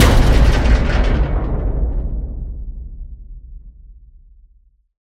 Heavy_Metal_Impact_Factory_Door_Vibrating_02_03.ogg